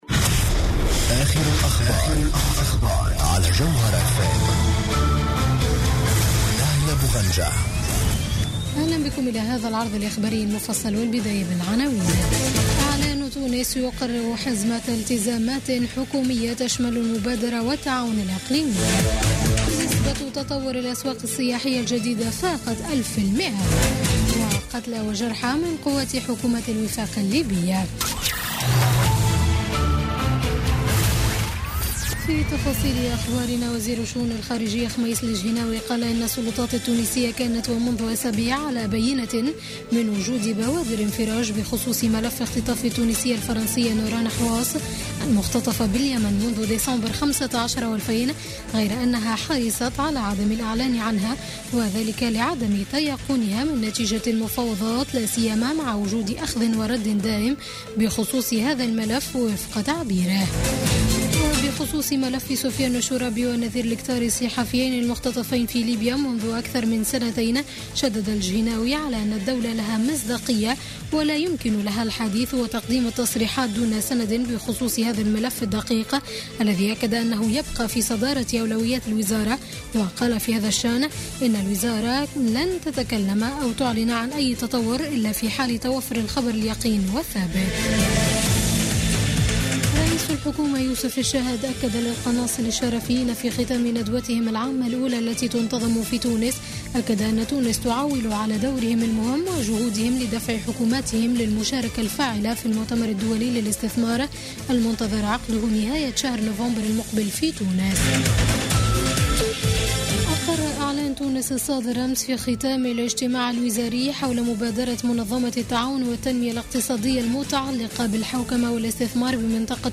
نشرة أخبار منتصف الليل ليوم الاربعاء 5 أكتوبر 2016